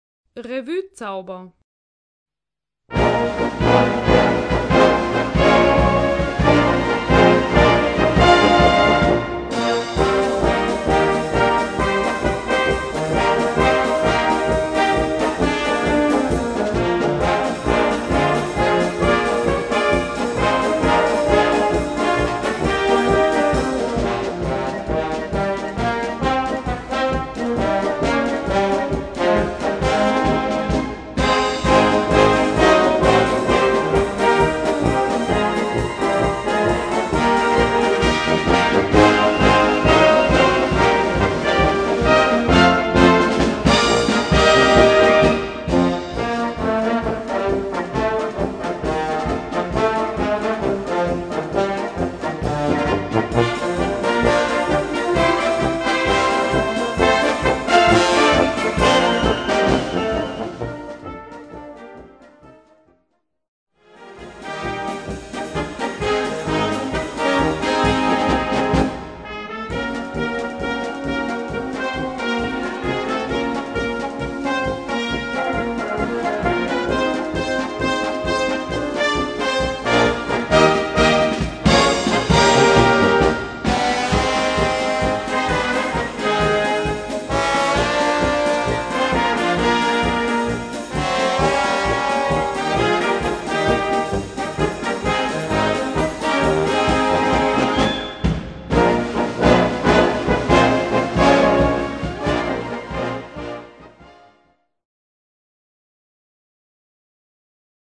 Gattung: Marsch
Besetzung: Blasorchester
moderner Show-Marsch